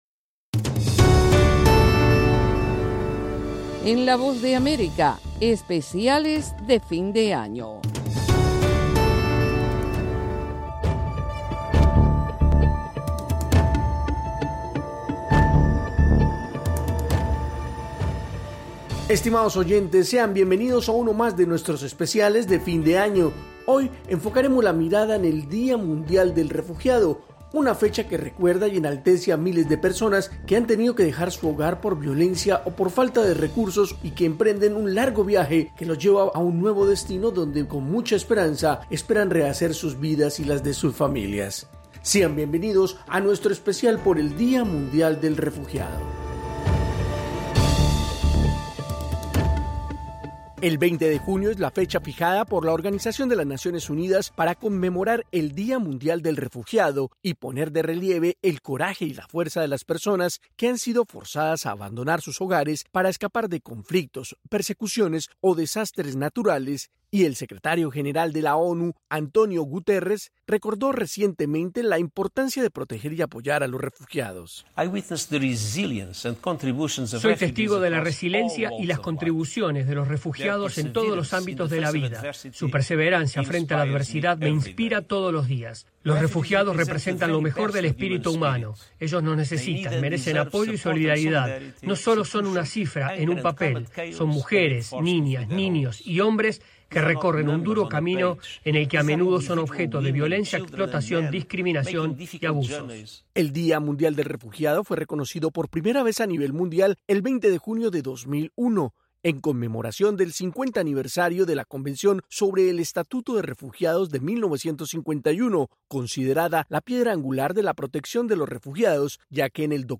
desde La Voz de America en Washington DC